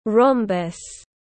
Hình thoi tiếng anh gọi là rhombus, phiên âm tiếng anh đọc là /ˈrɒm.bəs/.
Rhombus /ˈrɒm.bəs/